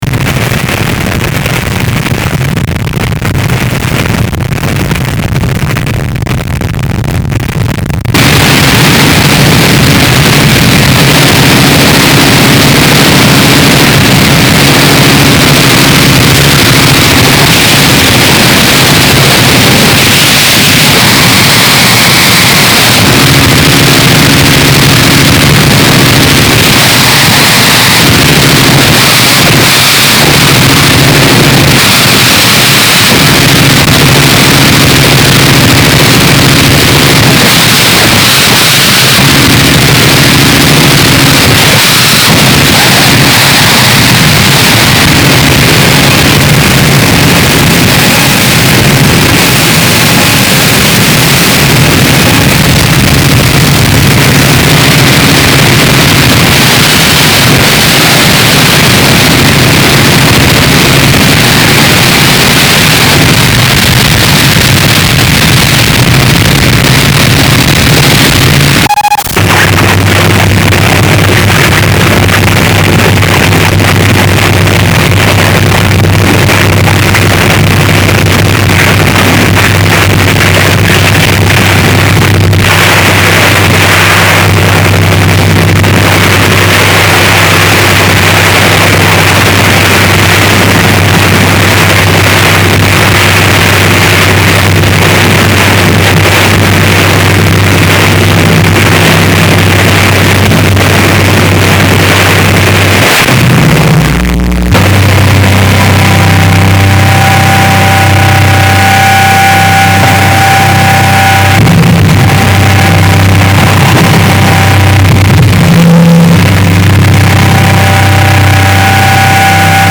heavy, low synth and tonal elements with loops and vocals